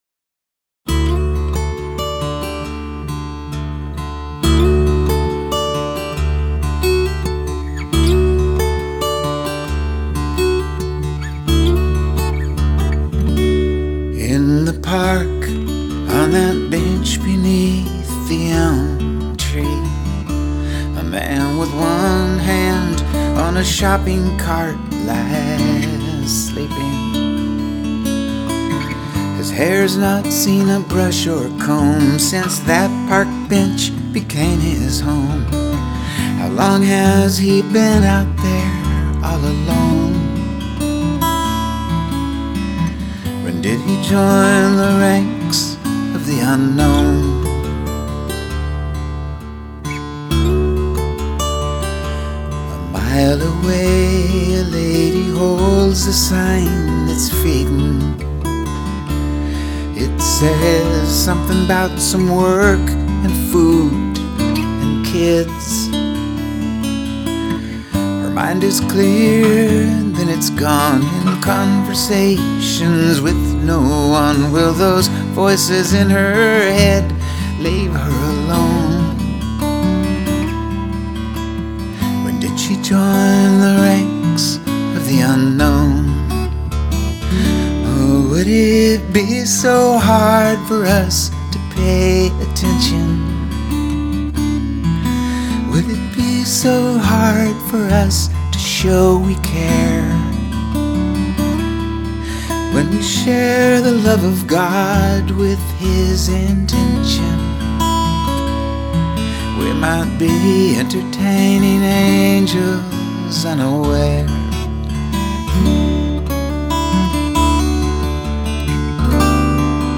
Intimate, yet full.